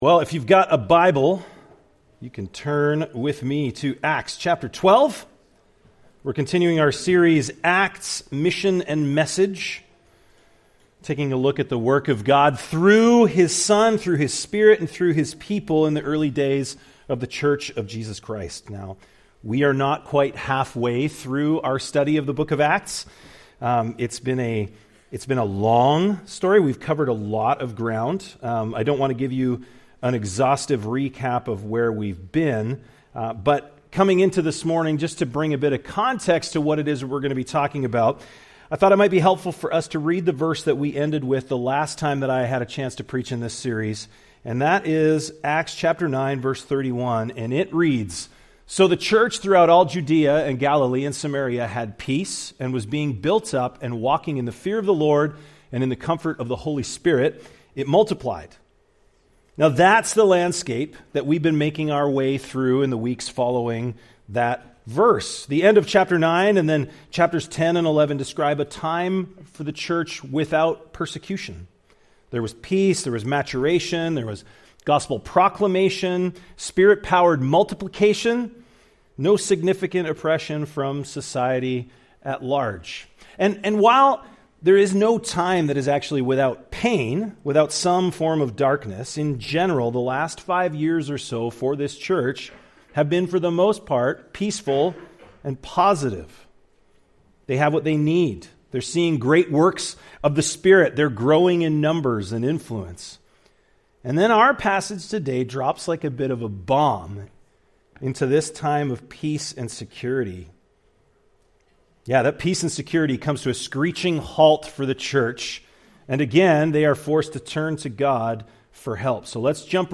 Part of our series, ACTS: Mission & Message (click for more sermons in this series).